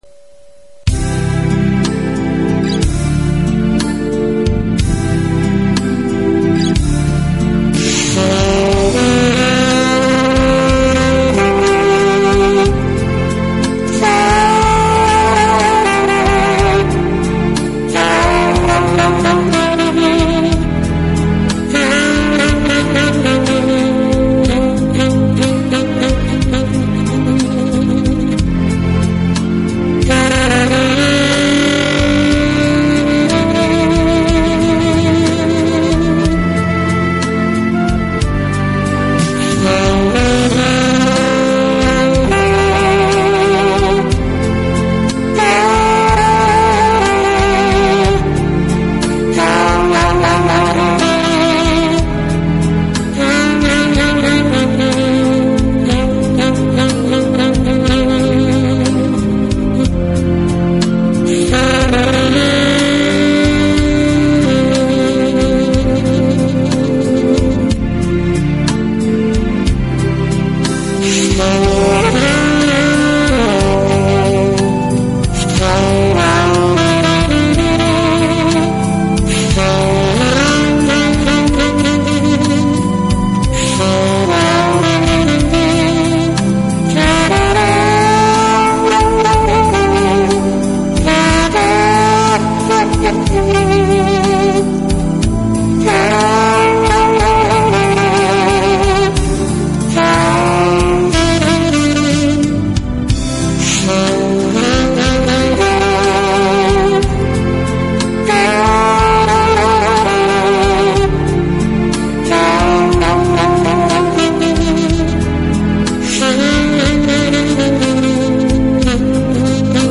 서브 톤(Sub tone)과 칼톤(Cal Tone = Gowling)
그리고 째즈에 많이 사용되는 플루터 톤(Flutter Tone)이
약간 가미되어 있는 느낌을 받습니다.
(앨토)